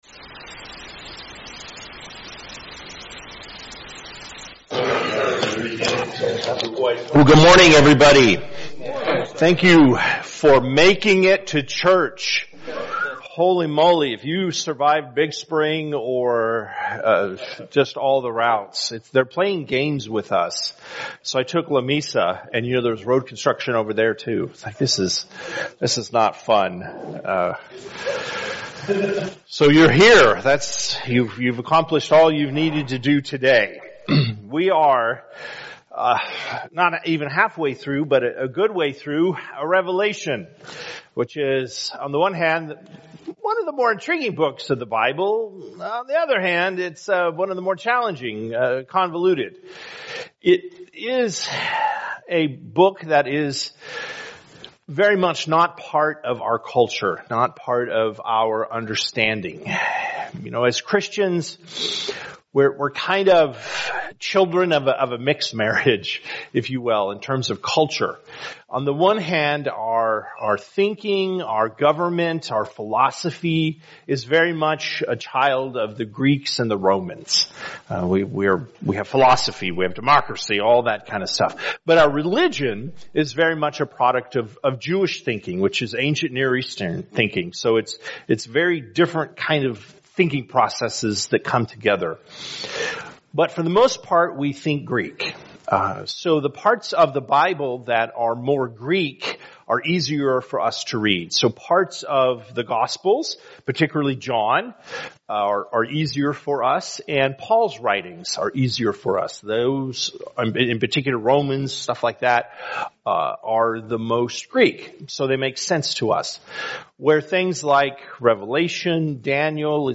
Men’s Breakfast Bible Study 8/13/24
Mens-Breakfast-Bible-Study-8.13.mp3